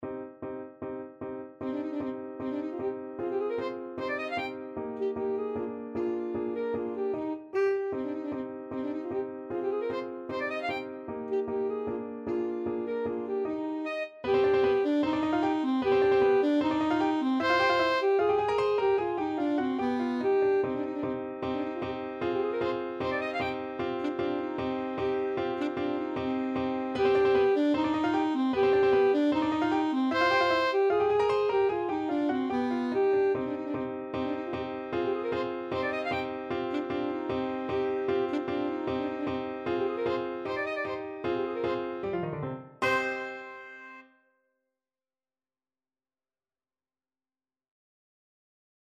Alto Saxophone
2/4 (View more 2/4 Music)
Allegro scherzando (=152) (View more music marked Allegro)
Classical (View more Classical Saxophone Music)